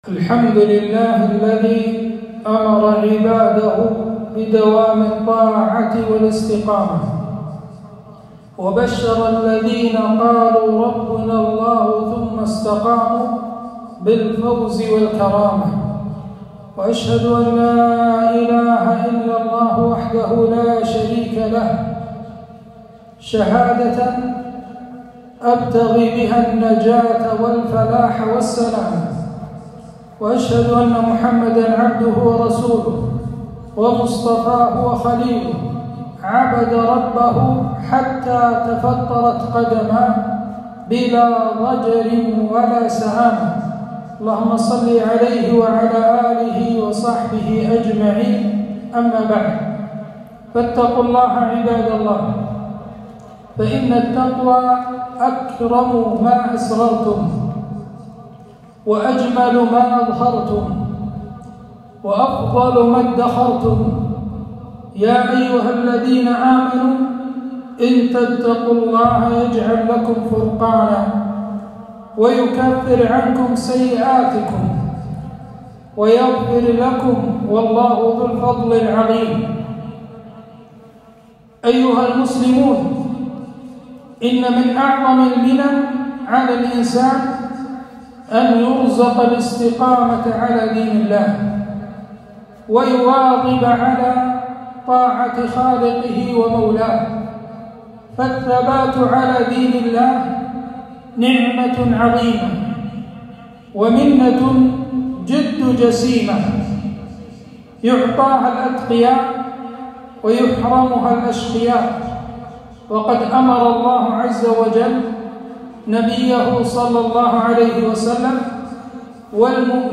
خطبة - الثبات على الطاعات